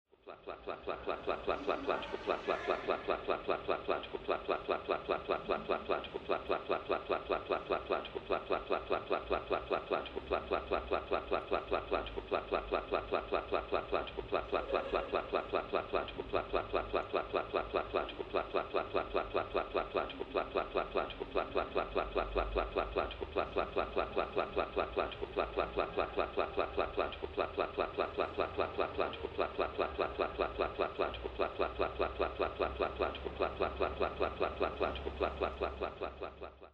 Elevator announcement glitch in rail station passage